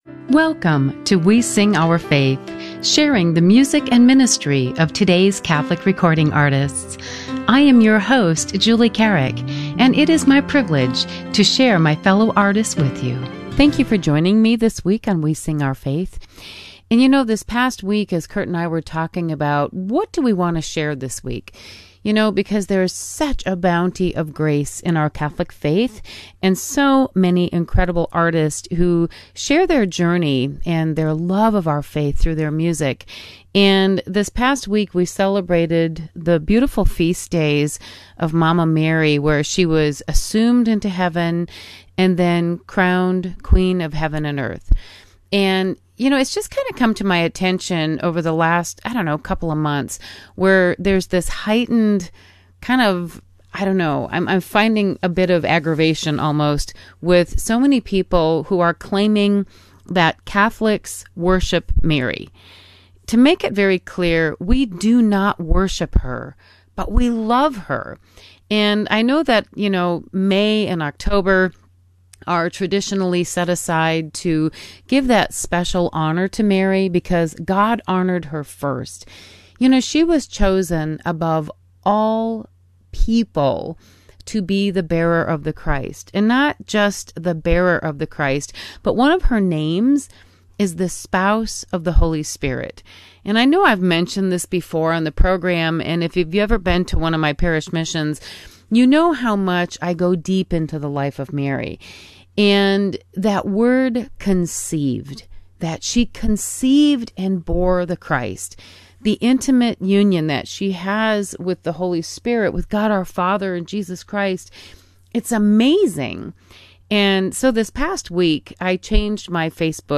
A new uplifting Catholic music show